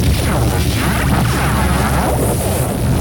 laser-beam-02.ogg